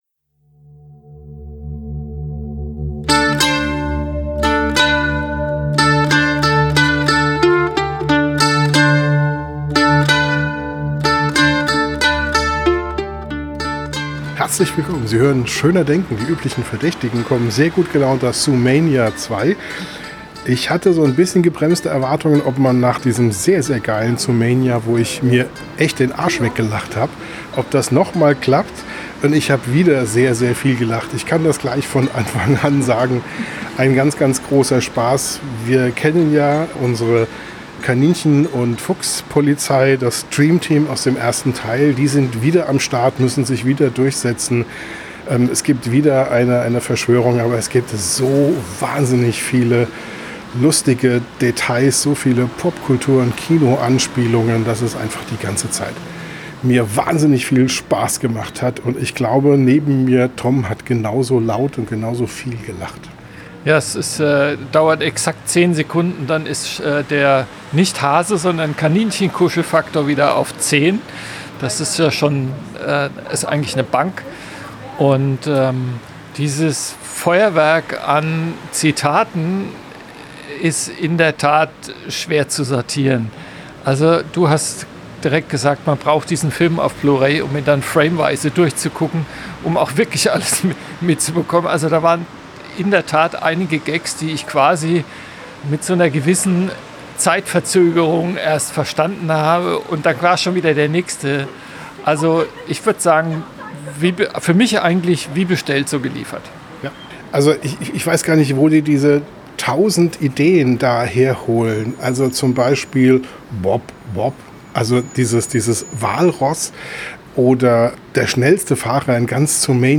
Der erste Eindruck direkt nach dem Kino